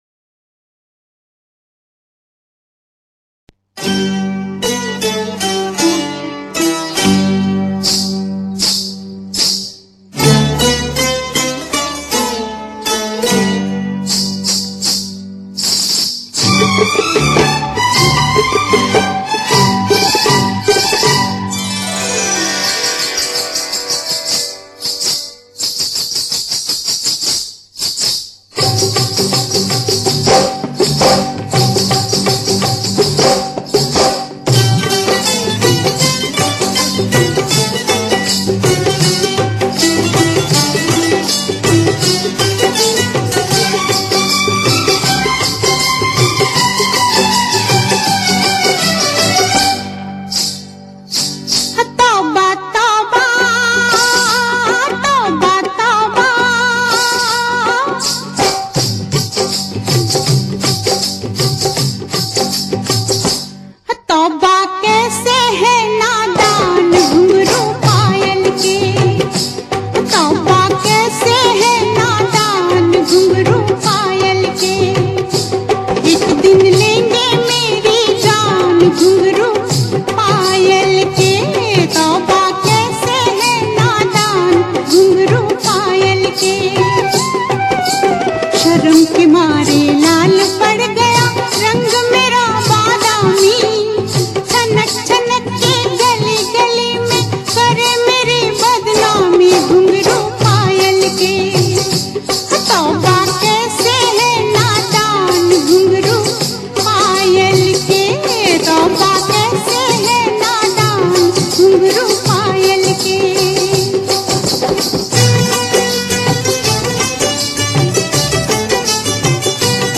Mela Competition Filter Song